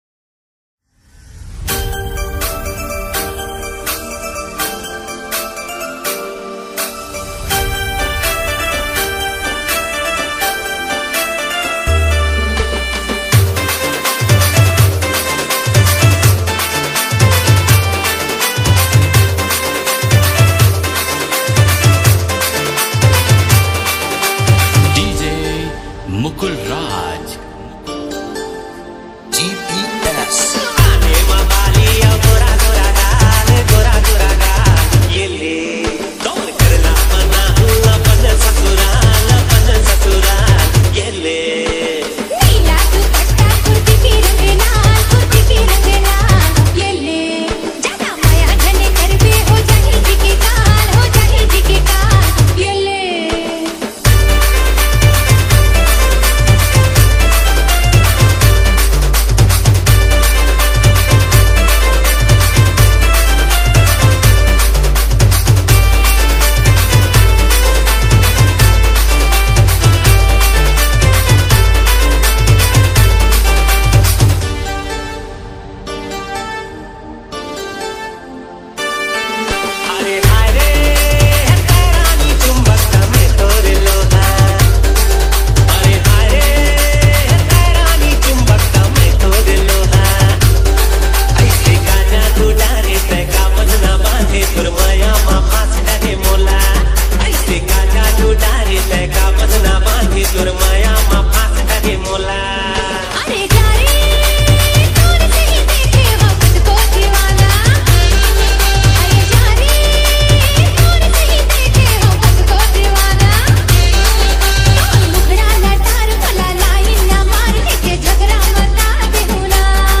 CG LOVE DJ REMIX